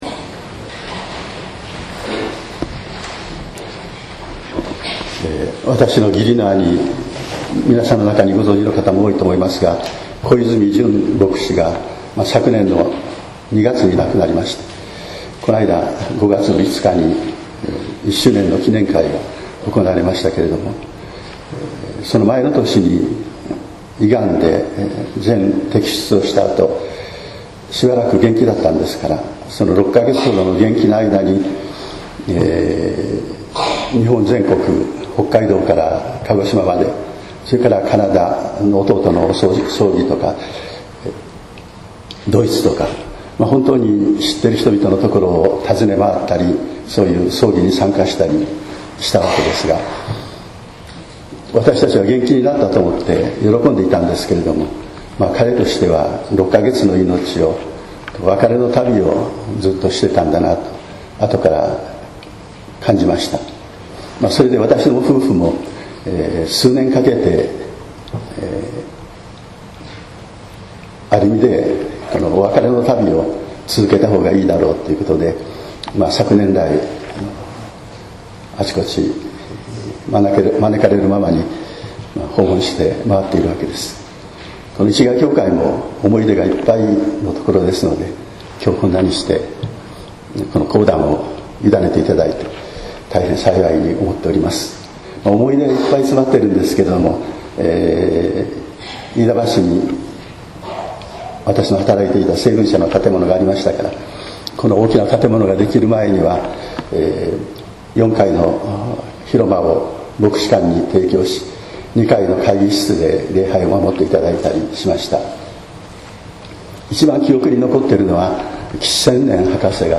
説教「空間（居場所）と関係（信頼）」（音声版）